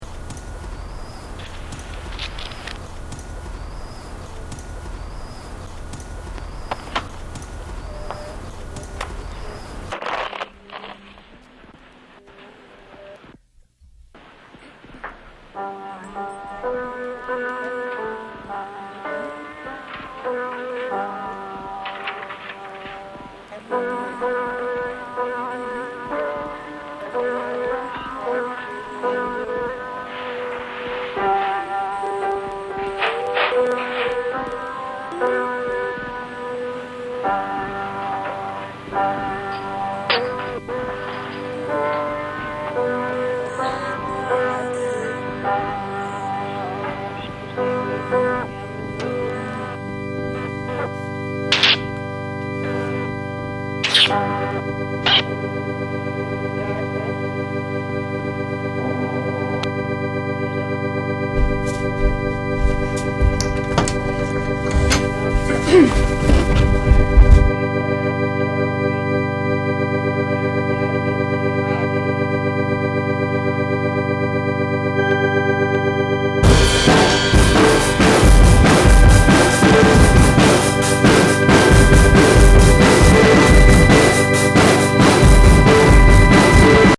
Known for their 4/8 track pop wizardry
folk pop songs